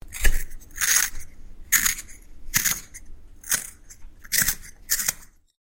Звук точилки для карандаша при заточке